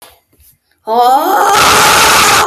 Weird Scream Bouton sonore